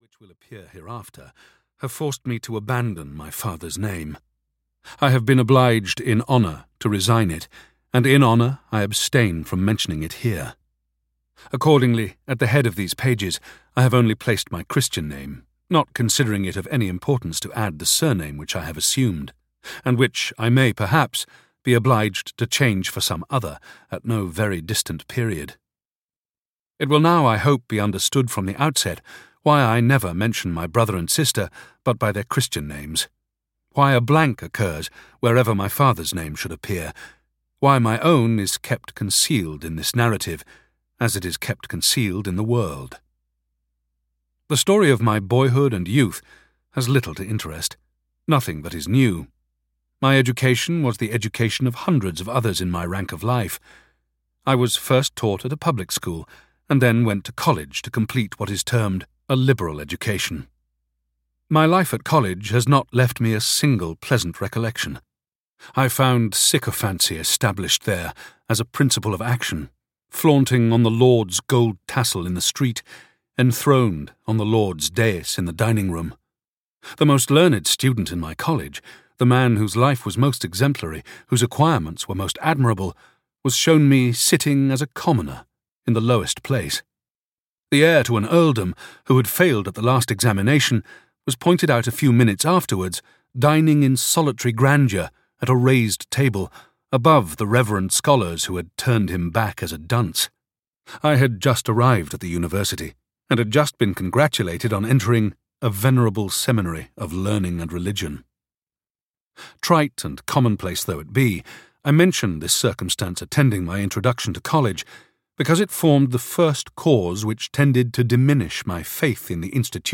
Basil (EN) audiokniha
Ukázka z knihy